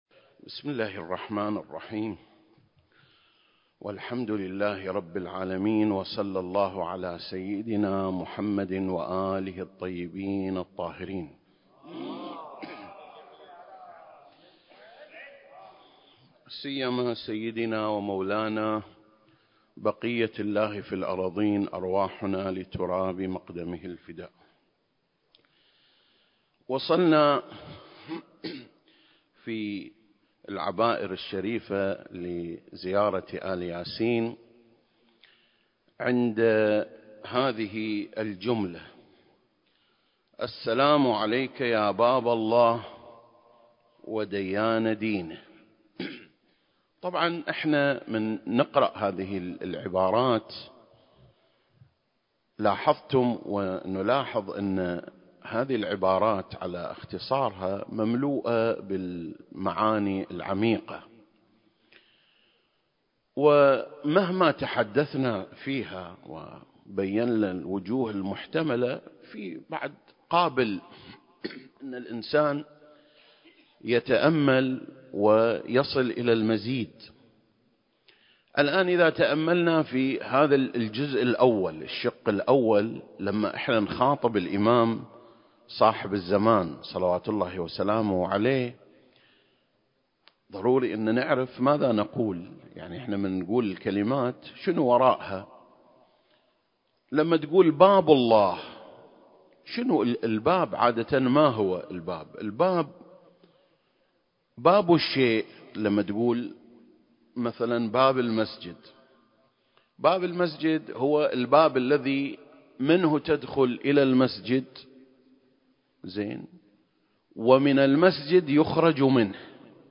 سلسلة: شرح زيارة آل ياسين (33) - باب الله (1) المكان: مسجد مقامس - الكويت التاريخ: 2021